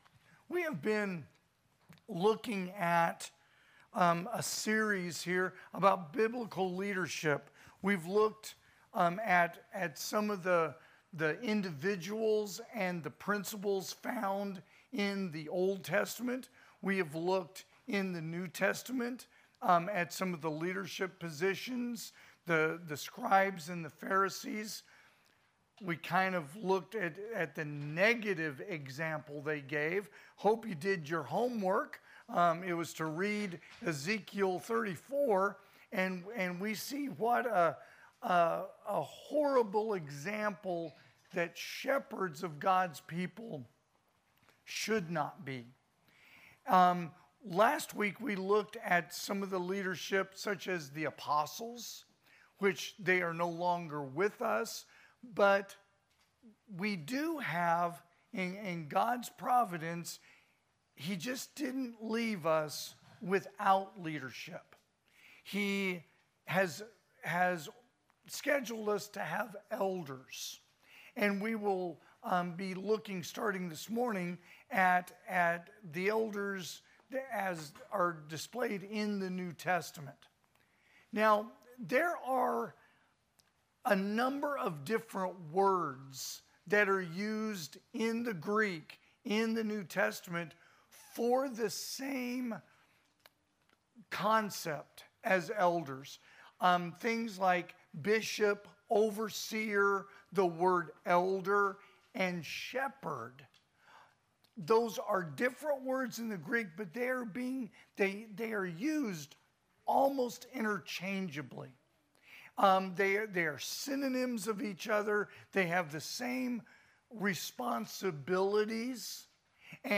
2025 (AM Worship) Biblical Leadership